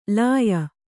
♪ lāya